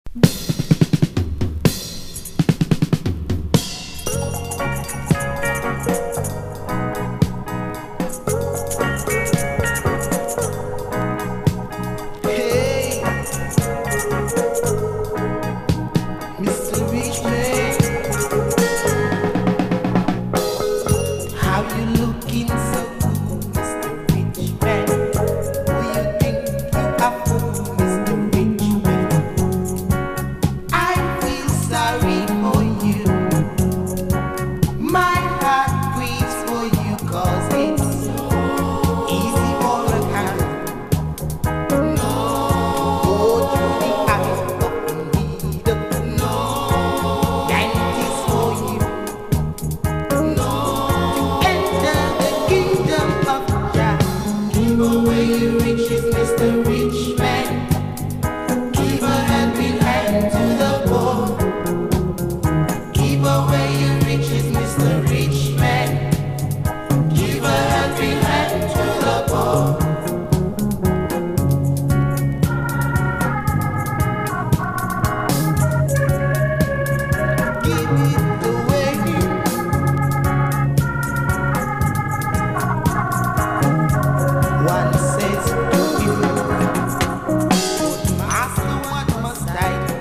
• REGGAE-SKA
気持ち良い爽やかなイントロから徐々にダブワイズしていく展開がとにかく最高!!
DUB / UK DUB / NEW ROOTS